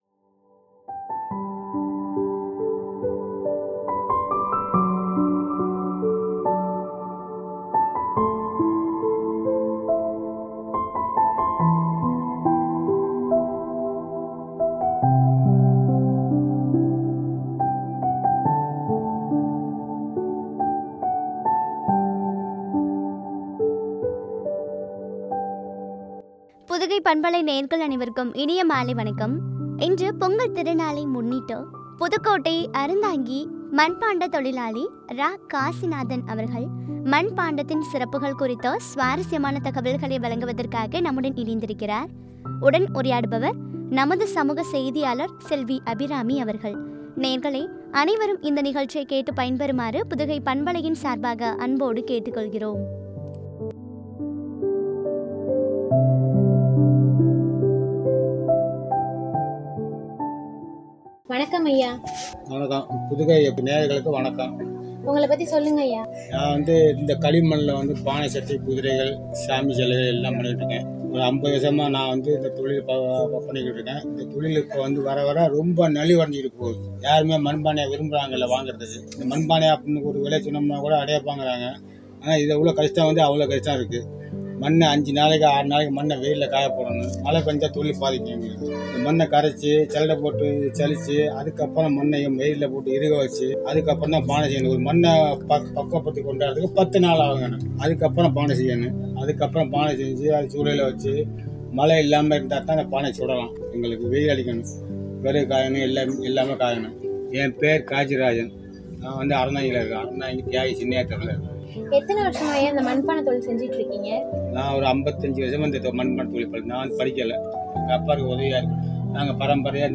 அவர்களின் பொங்கலிற்கான மண்பாண்டங்கள் என்ற தலைப்பில் வழங்கிய உரையாடல்.